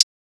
TS HiHat_3.wav